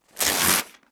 Arrancar una hoja de papel de un block